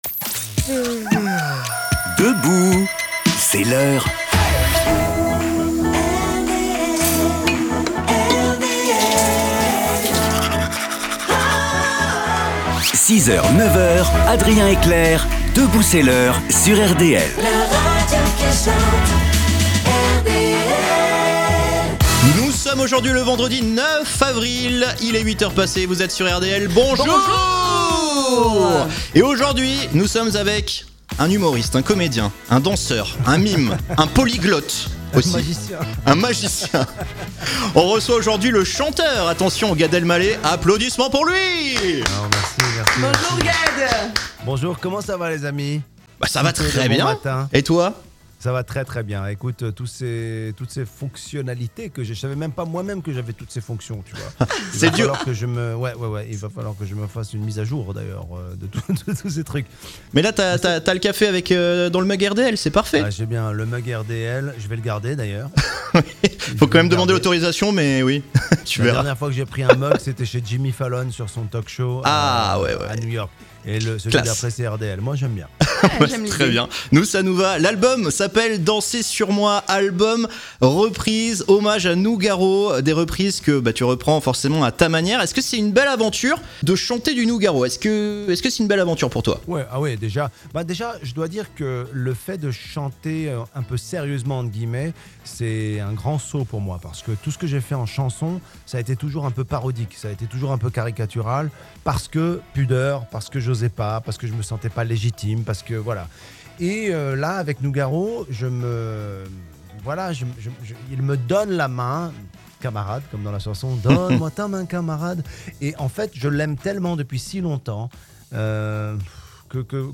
A l'occasion de la sortie de son album hommage à Claude Nougaro "Dansez sur moi", réécoutez l'interview de Gad Elmaleh sur RDL !